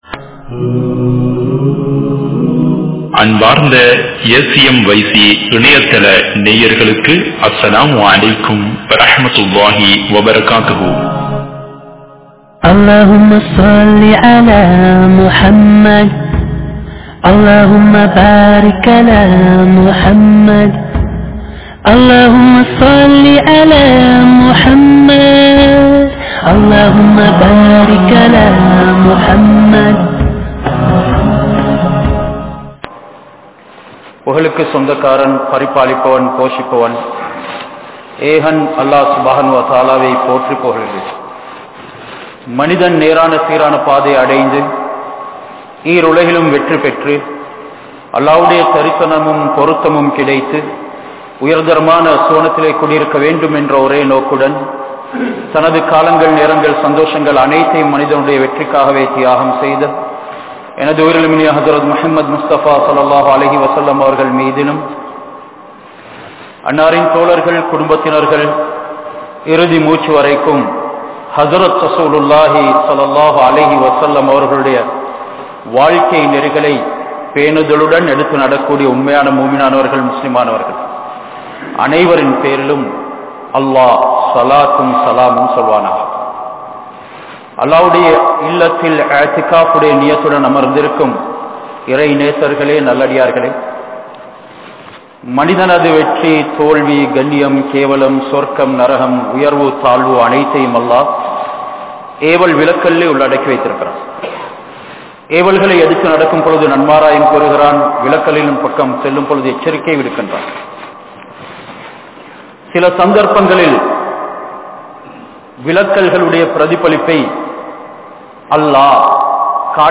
Allah`vin Payaththaal Alungal (அல்லாஹ்வின் பயத்தால் அழுங்கள்) | Audio Bayans | All Ceylon Muslim Youth Community | Addalaichenai